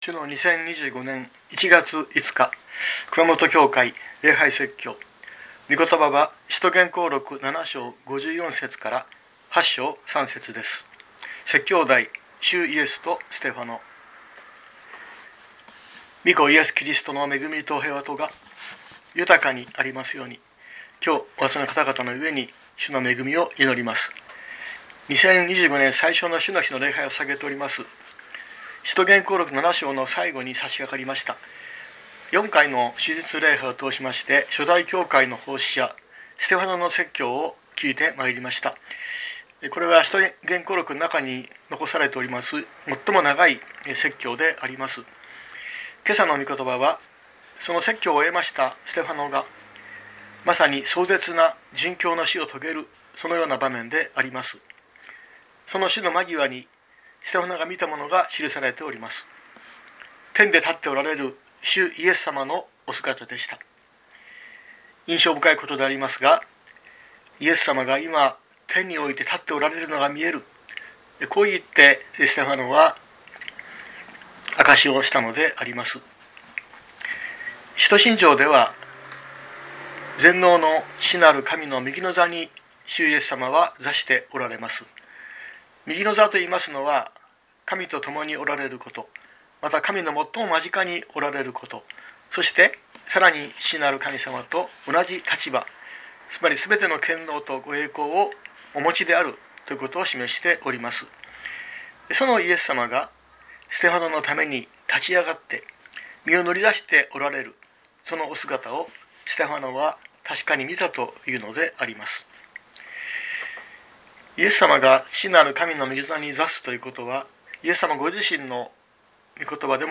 2025年01月05日朝の礼拝「主イエスとステファノ」熊本教会
熊本教会。説教アーカイブ。